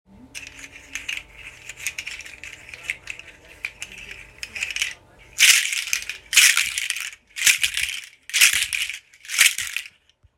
• large seed shaker originally from Togo
• loud hollow clacking sound
16 seed loop handle audio sample